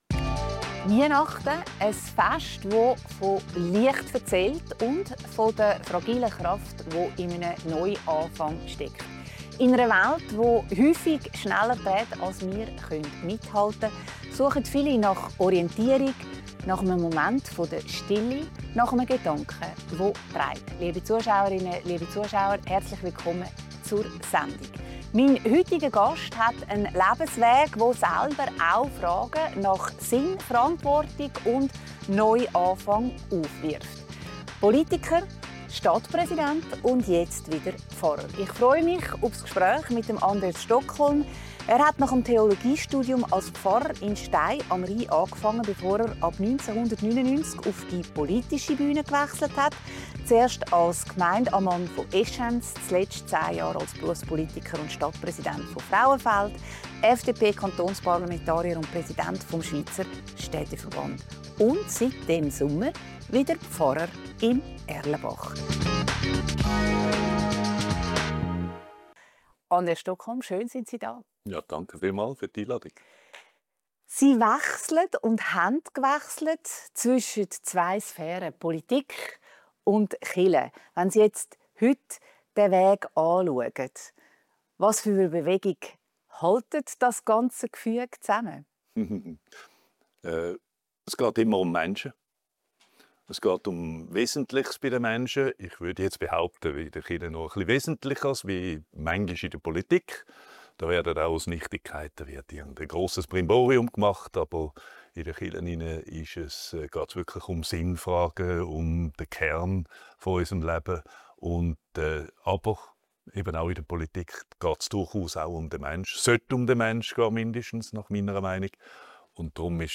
im Gespräch mit Anders Stokholm, Pfarrer von Erlenbach und ehemaliger Stadtpräsident von Frauenfeld über sozialen Zusammenhalt und kulturelle Traditionen.